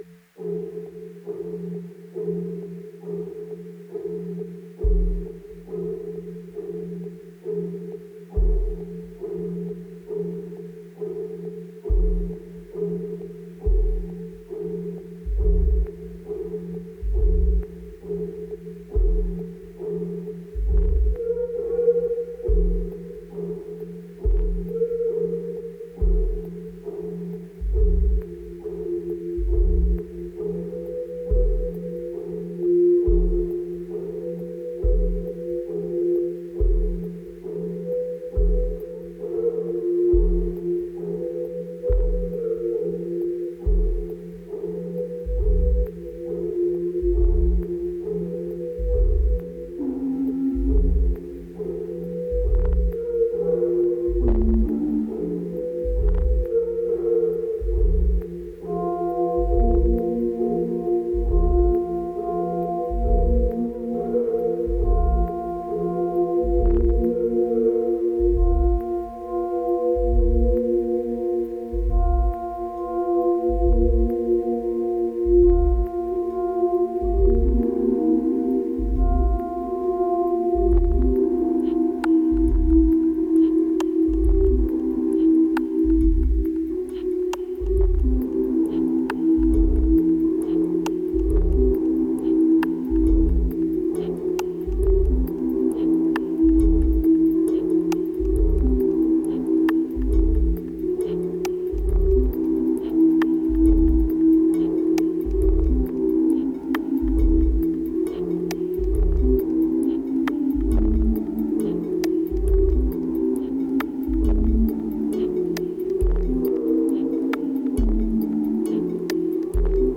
2387📈 - 60%🤔 - 68BPM🔊 - 2017-04-08📅 - 191🌟